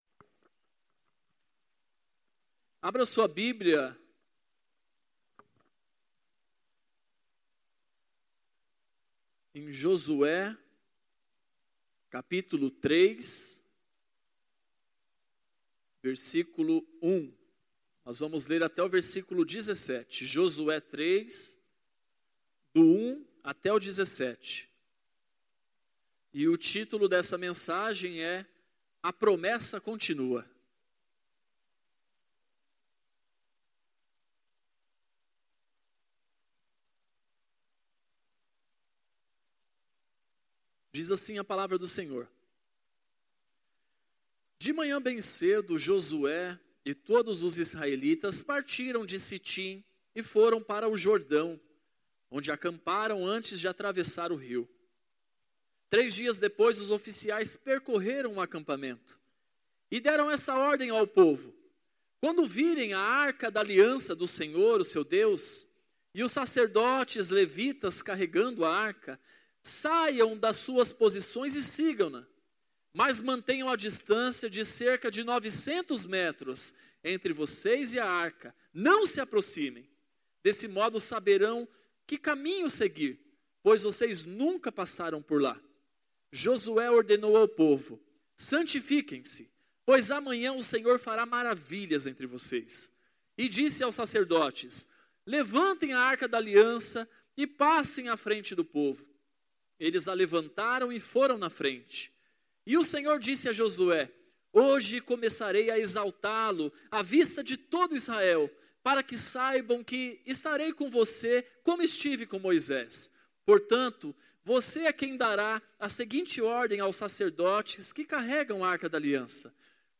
Mensagem do dia 25 de Agosto de 2019.